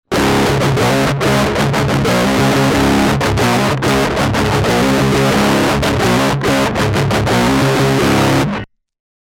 Cette une pédale de distortion orientée Fuzz qui peut aussi s'utiliser aussi bien qu'avec une guitare qu'avec une basse.
Enregistrer directement dans une table yamaha MG-12/4, avec une carte ECHO MIA MIDI.
Le son est neutre,sans traitement.
TONE : 70 SUSTAIN :70 :
Big muff T-70 S-70.mp3